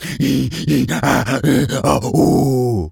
gorilla_chatter_03.wav